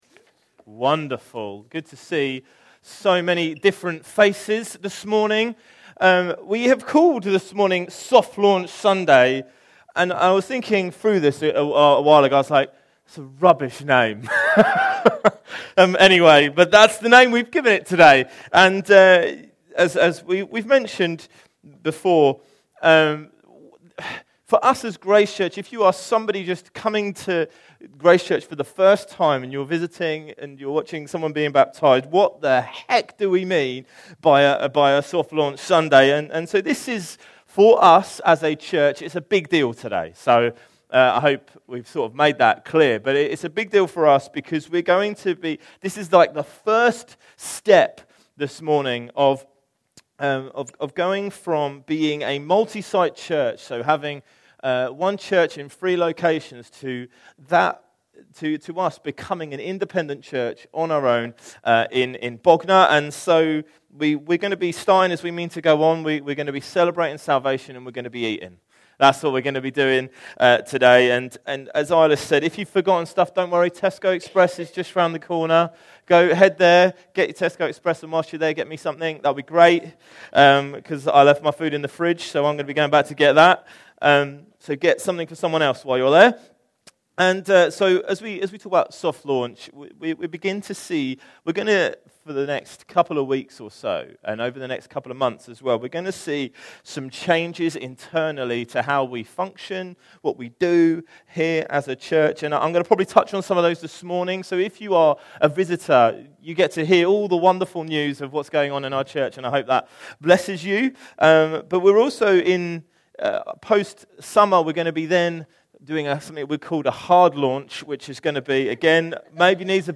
Series: Other Sermons 2025